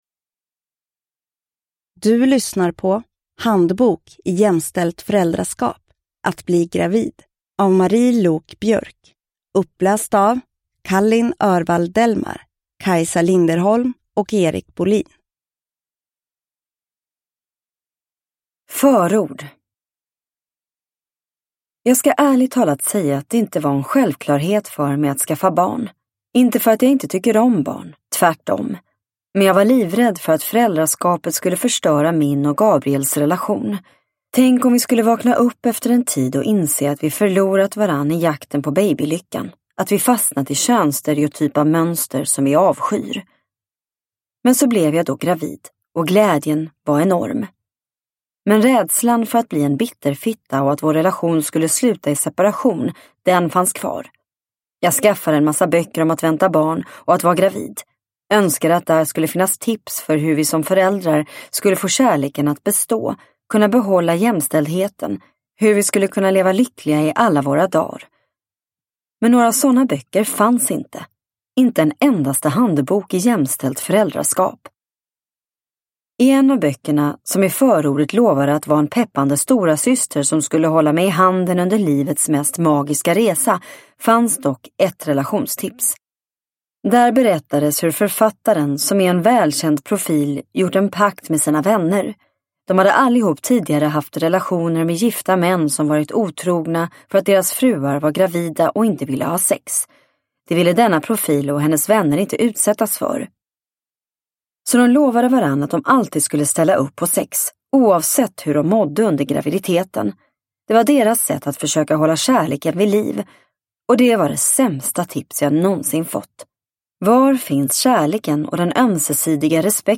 Handbok i jämställt föräldraskap - Att bli gravid – Ljudbok – Laddas ner